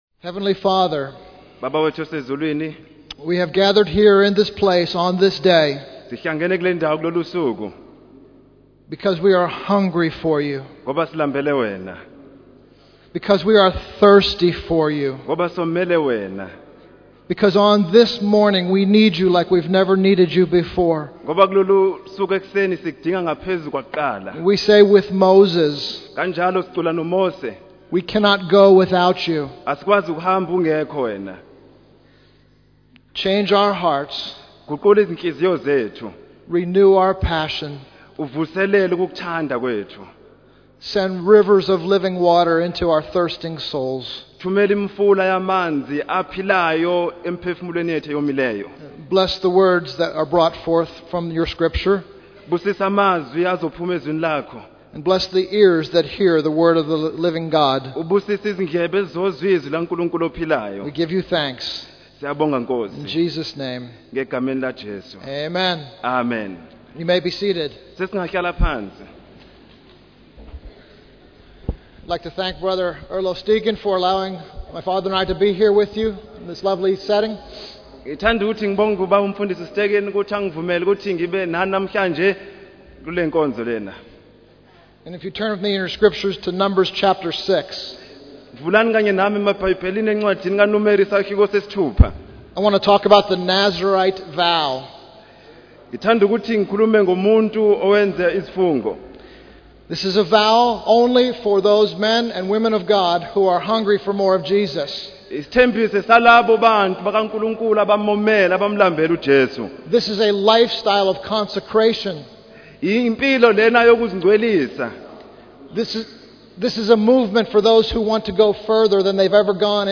In this sermon, the speaker focuses on Numbers chapter 6 and the concept of the Nazarite vow. The vow is seen as a way for both men and women to consecrate themselves to the Lord and go deeper in their relationship with Jesus.